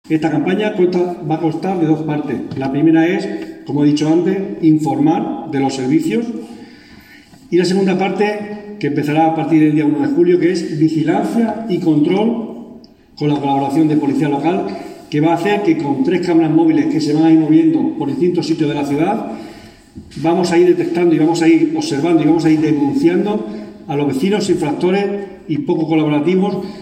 Declaraciones del concejal de Limpieza Viaria, Antonio Expósito